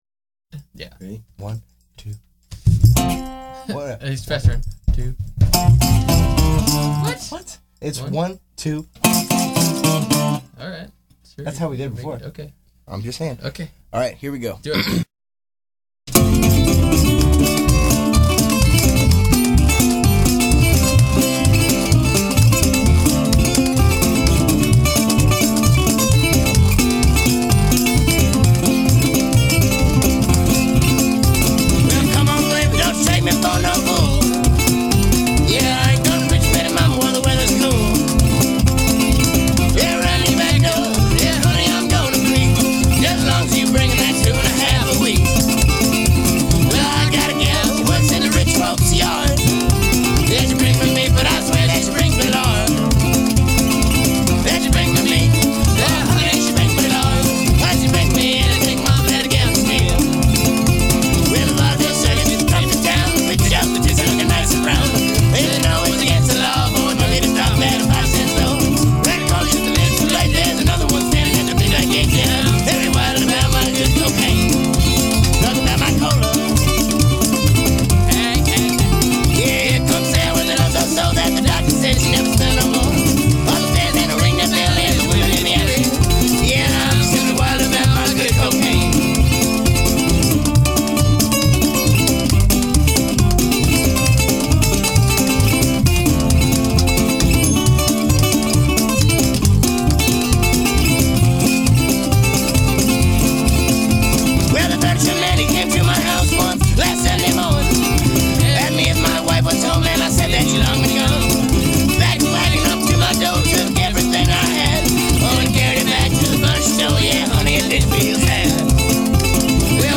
A ragtime gallimaufry from Eugene, Oregon U$A